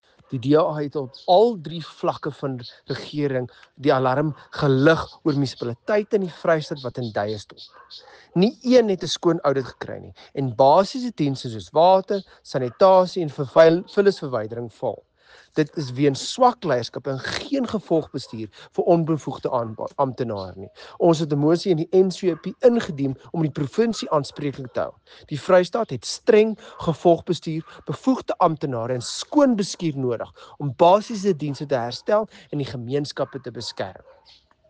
Afrikaans soundbites by Igor Scheurkogel MP, and